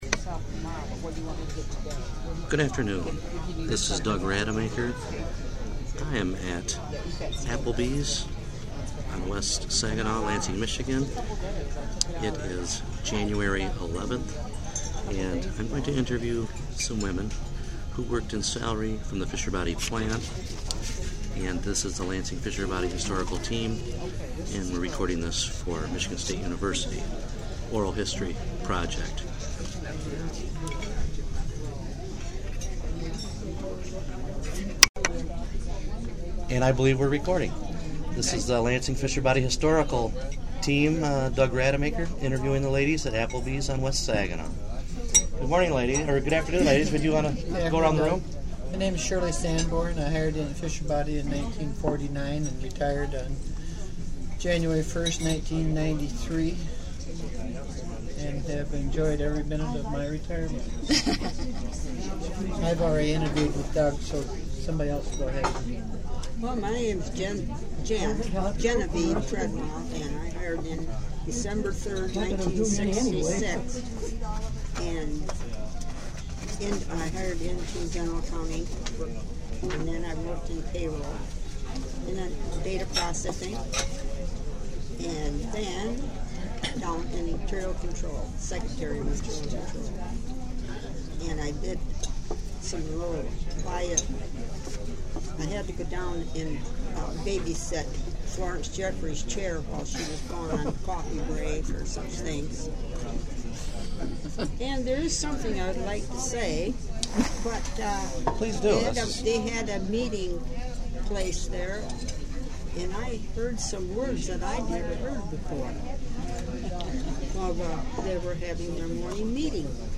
The Fisher Body Old Crates, a group of women who retired from salaried clerical jobs at the Fisher Body plant in Lansing, MI, offer a female perspective of work in the factory during careers spanning five decades